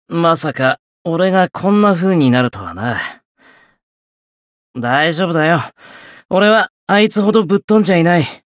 觉醒语音 想不到我会变成这副模样。